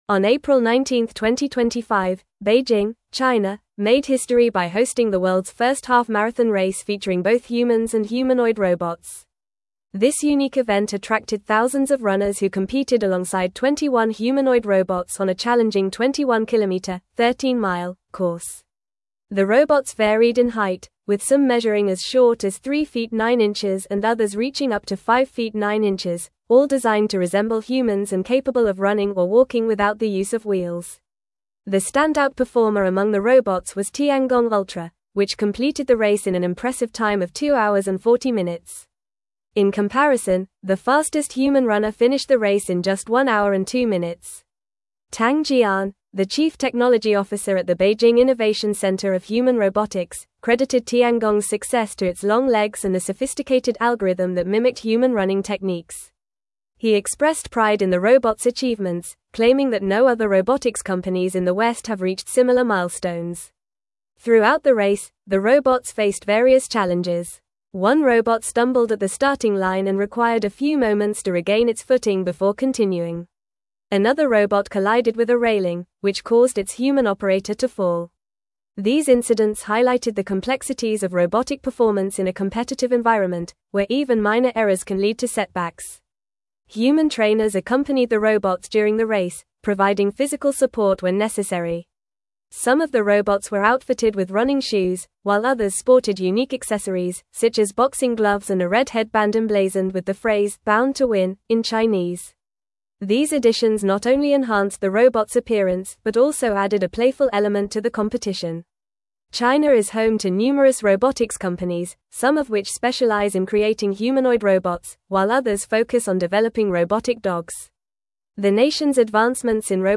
Fast
English-Newsroom-Advanced-FAST-Reading-China-Hosts-Historic-Half-Marathon-with-Humans-and-Robots.mp3